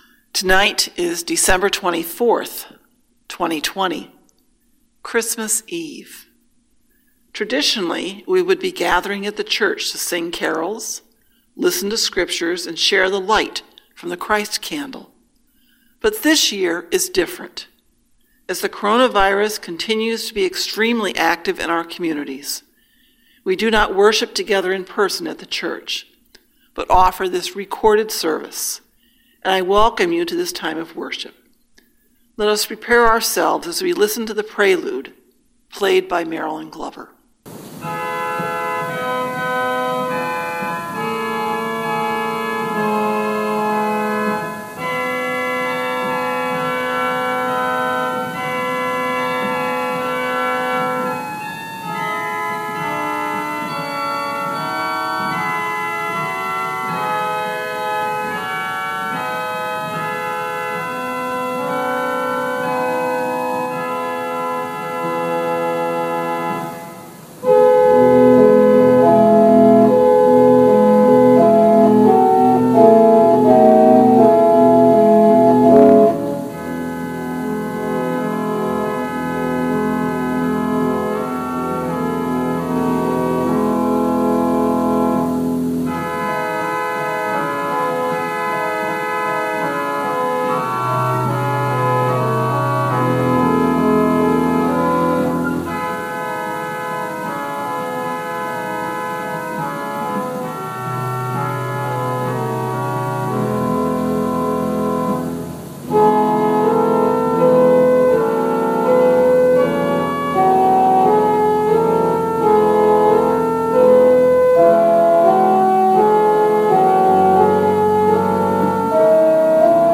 Christmas Eve Worship Service 2020 | First Baptist Church, Malden, Massachusetts
Organ Prelude
Meditation on Matthew 1:23